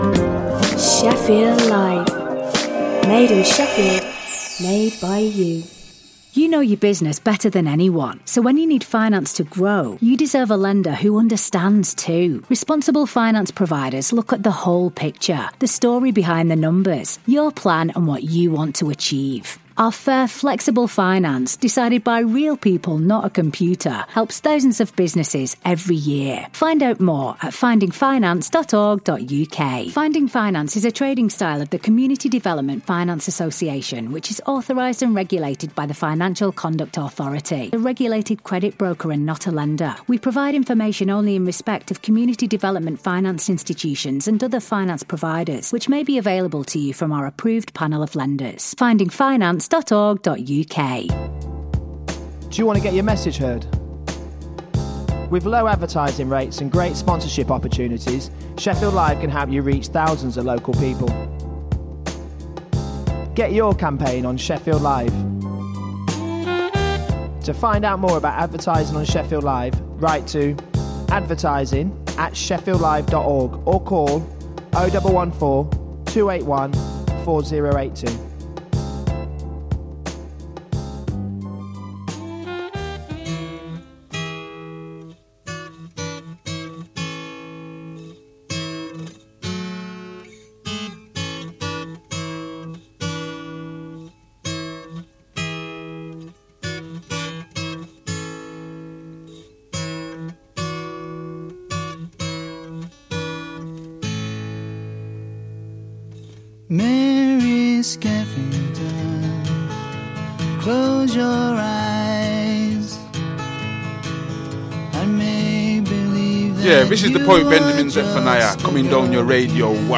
Talking Balls is a tongue in cheek sports chat show looking at the latest sports news and stories, with both interviews, previews and competitions